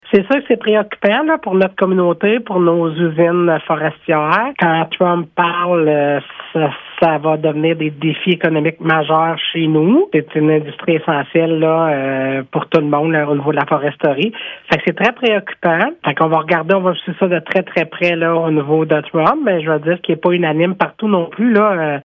La préfète, Chantal Lamarche, affirme que cette situation préoccupe au plus haut point la MRC :